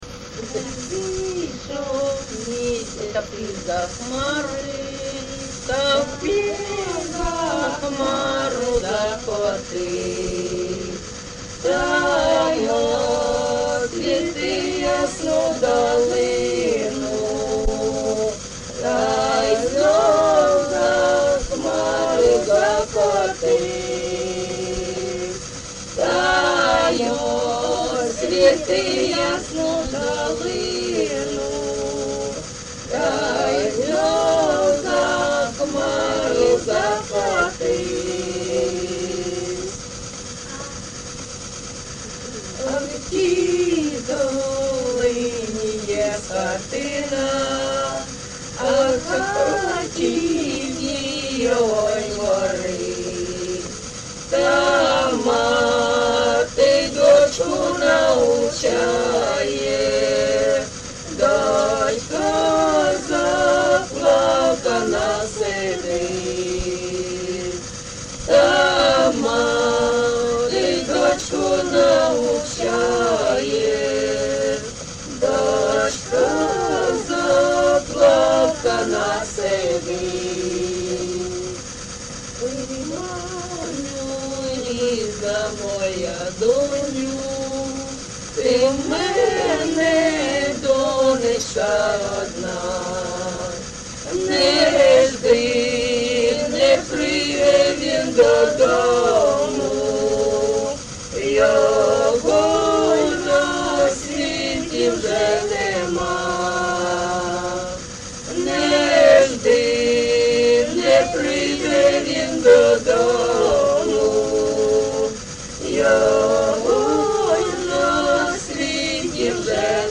ЖанрПісні з особистого та родинного життя
Місце записус. Рідкодуб, Краснолиманський (Лиманський) район, Донецька обл., Україна, Слобожанщина